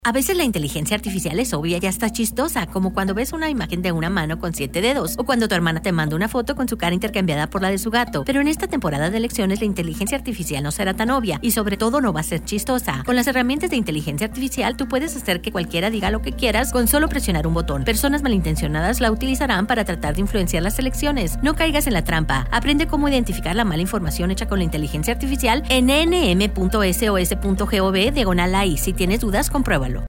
Seeing Is No Longer Believing Radio Ad - Spanish
SOS-30SEC-AI-Radio-Ad_Spanish.mp3